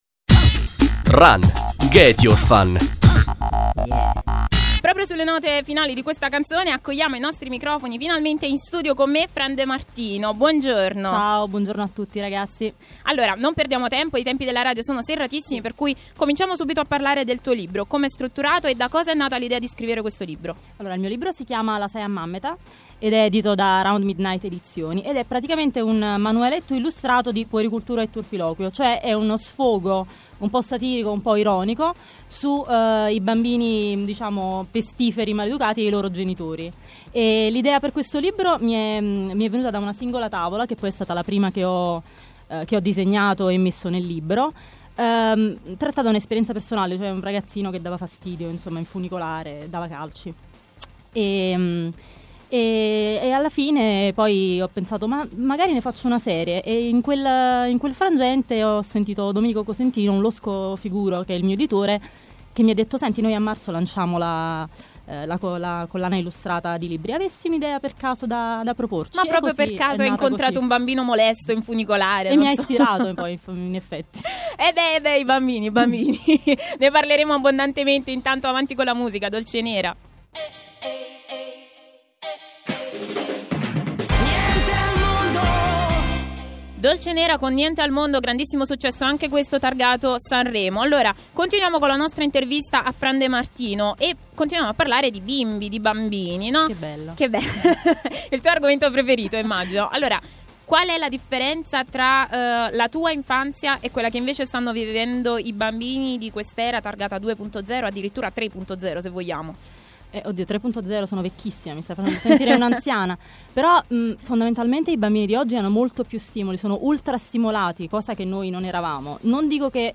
Intervista su “Reload”, RunRadio, ottobre 2014